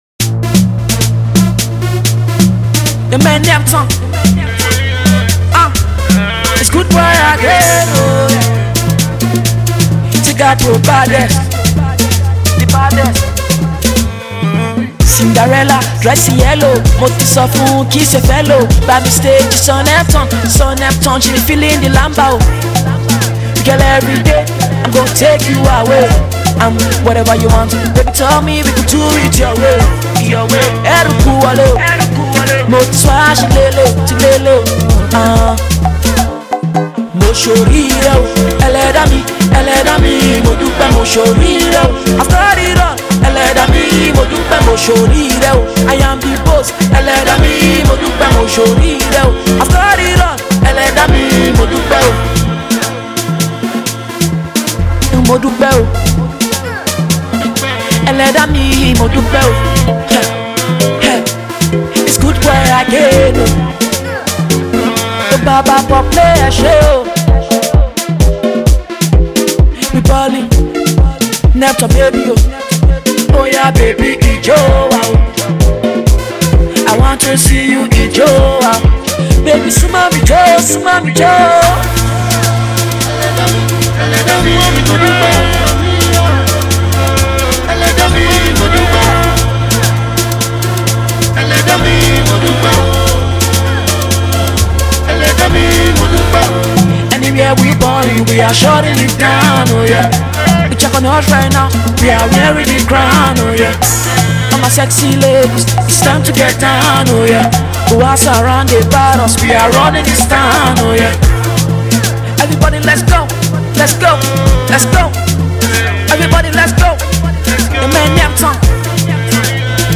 Talented Fast Rising Recording and Performing Pop Artiste
Overview – A Rhythm and Vibe.